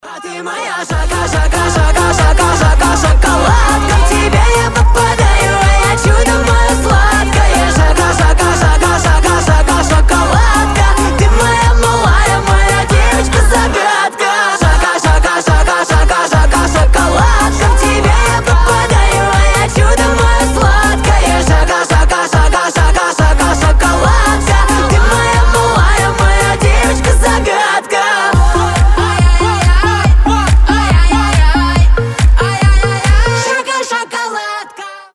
• Качество: 320, Stereo
веселые
быстрые
динамичные